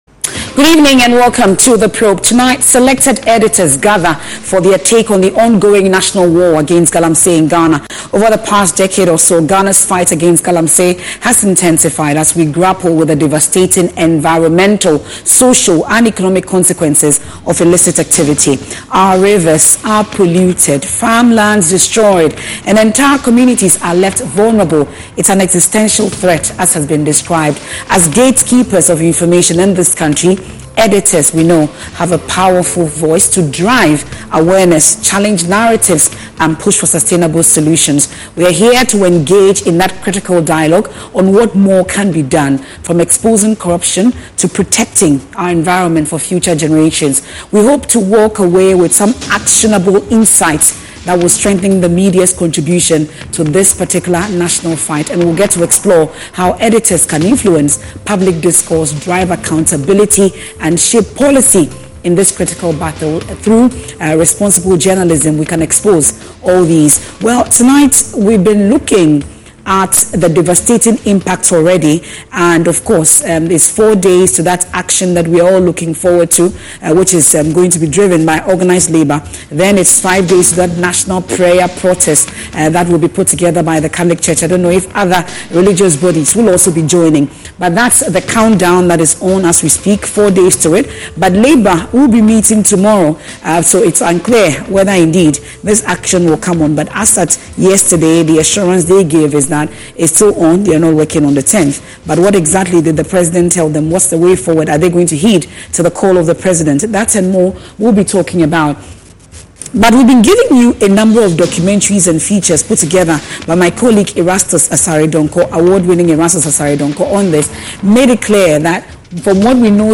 What would you ask a politician if you had the chance? Welcome to The Probe, an audience-driven news interview program that collects questions from the general public and demands answers from political actors, duty bearers and relevant stakeholders.